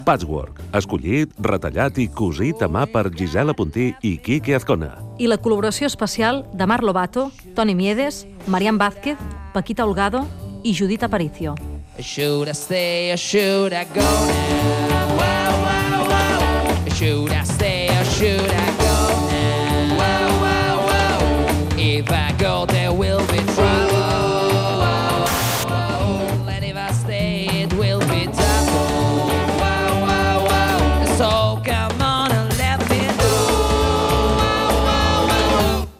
Entreteniment
Fragment extret de l'arxiu sonor de COM Ràdio.